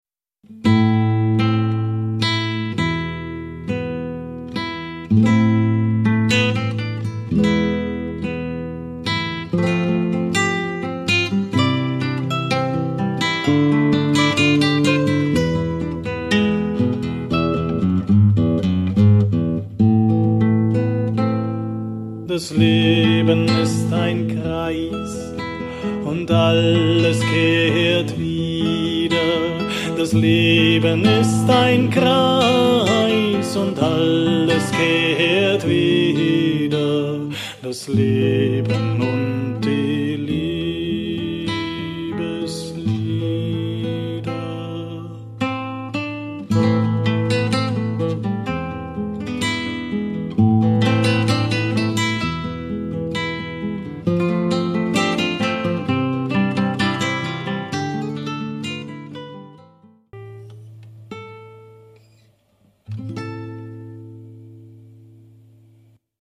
Gitarre, Flöte, Blockflöte, Klavier, Gesang